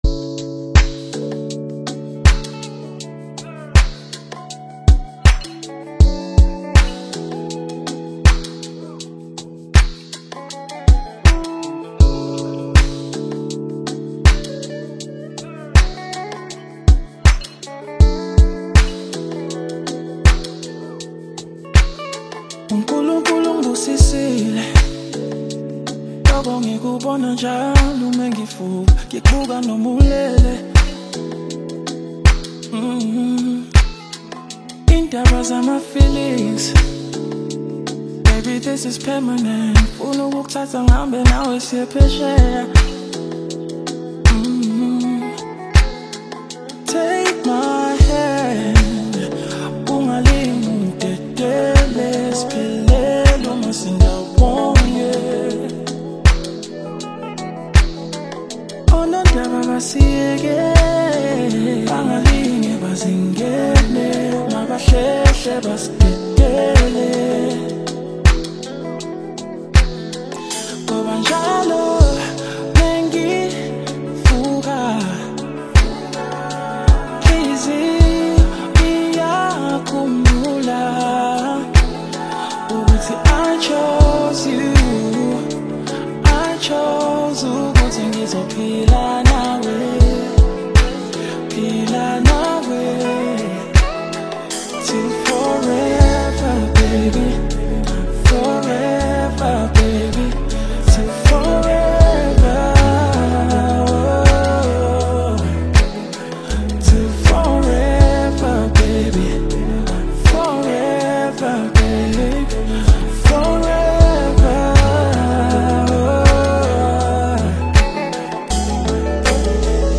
AudioR&BSouth African Music
romantic Afro-Pop single